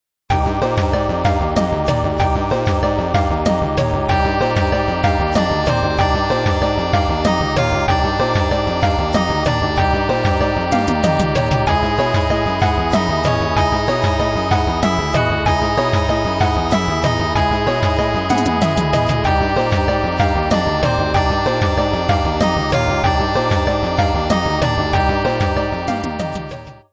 お遊びなので、パート数も最低限で、音色やボリュームも適当です。
（ヘッドホンで音量調整しているのでスピーカーで聴くと低音が小さいはずです・・・）
019b 　[019]のゲーム風（Cm） 06/11/17